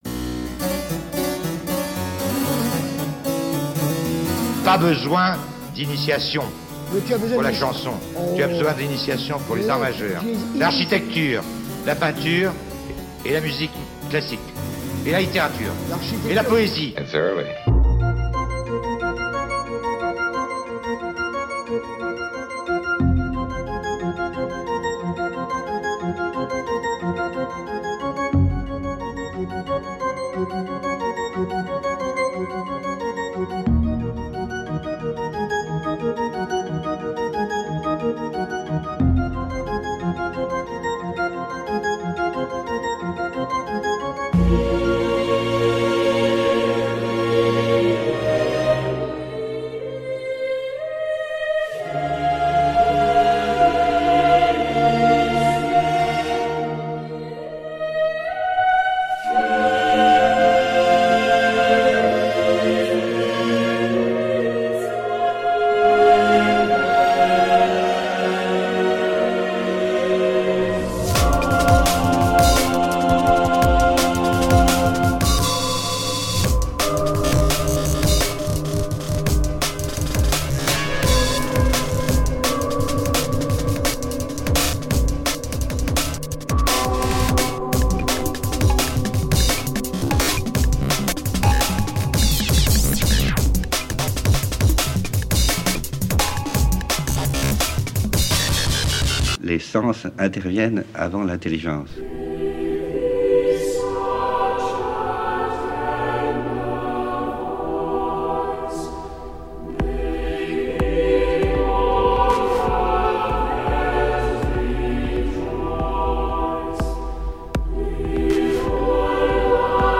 Tracklist (répliques et musiques par ordre d'apparition) :